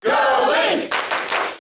Link's cheer in the US and PAL versions of Brawl.
Link_Cheer_English_SSBB.ogg